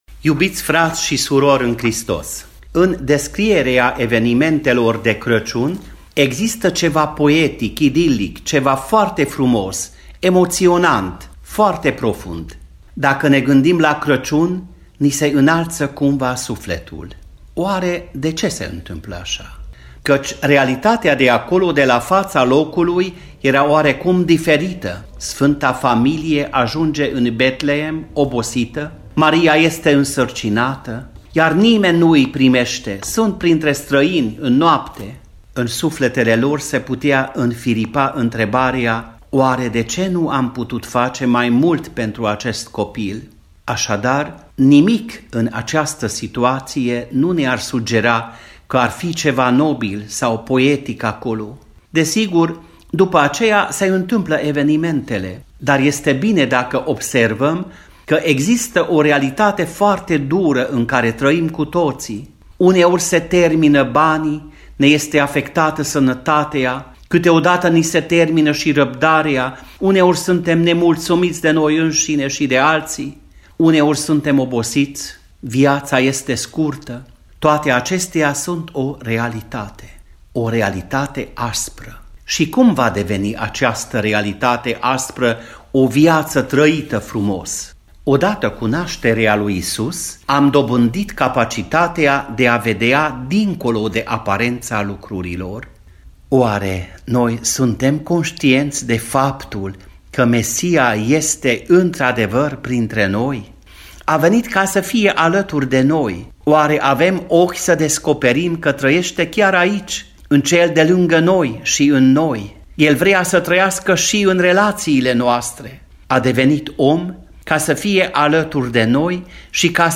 Mesajul de Crăciun la episcopului romano-catolic al diecezei de Timișoara, Josef Csaba Pal
Predica-de-Craciun-Josef-Csaba-Pal-Episcop-romano-catolic-al-diecezei-de-Timisoara-FONO.mp3